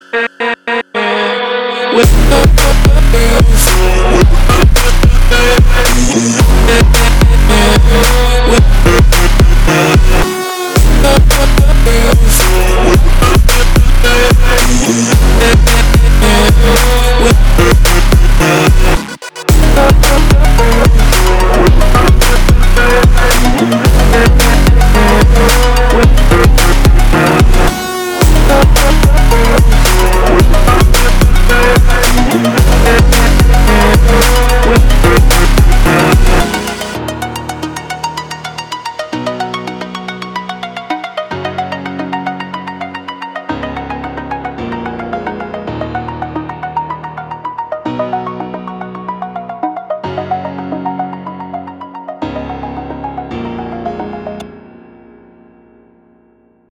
EDM
Trap